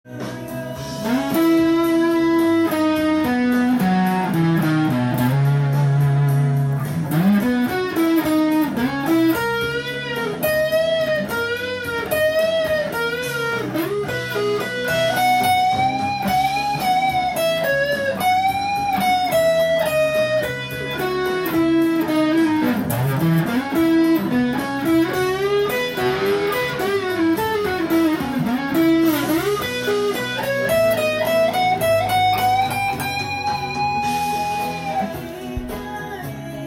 耳コピtab譜
音源にあわせて譜面通り弾いてみました
Eメジャースケールを使用したギターソロを弾いています。
そのkeyがEメジャーです。